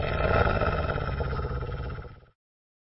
wolf_injured.mp3